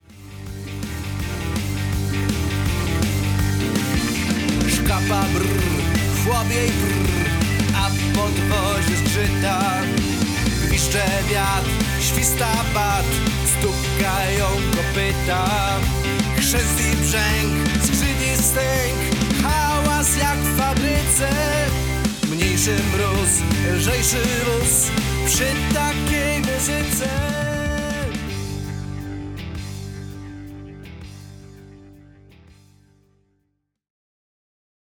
Zimowa, klimatyczna aranżacja